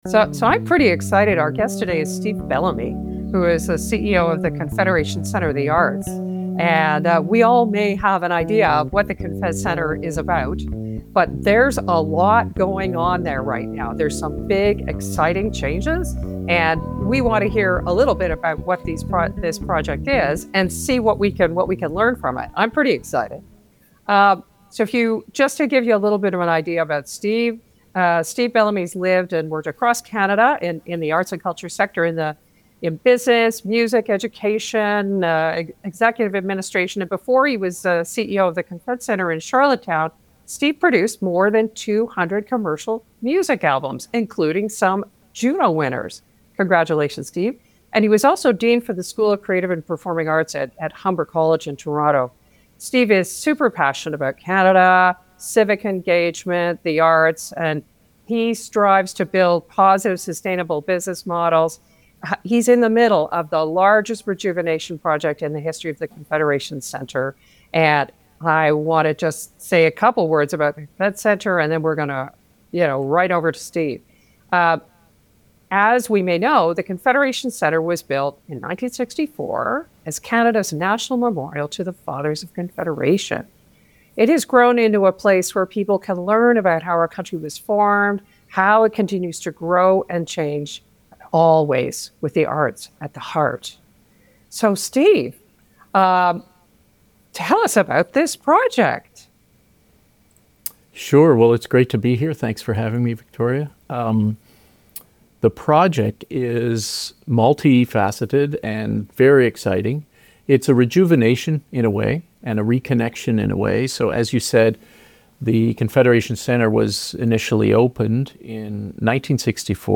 Join us for an engaging conversation